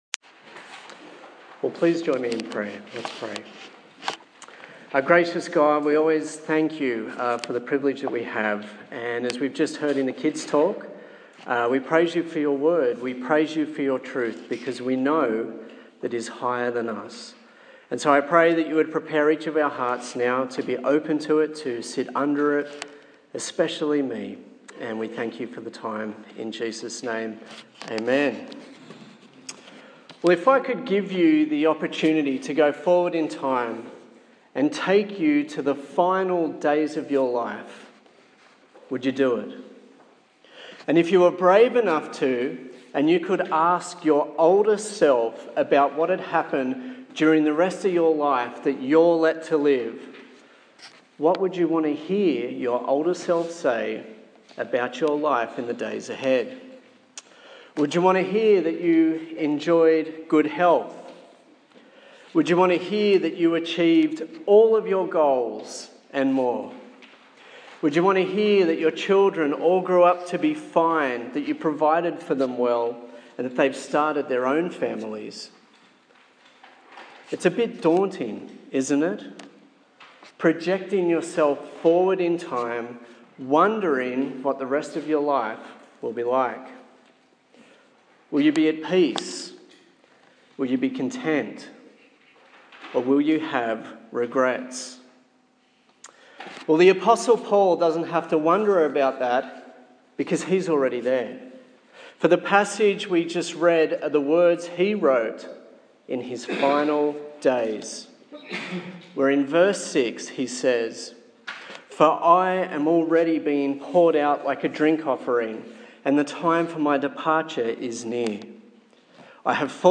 02/08/2015 For the Time will Come Preacher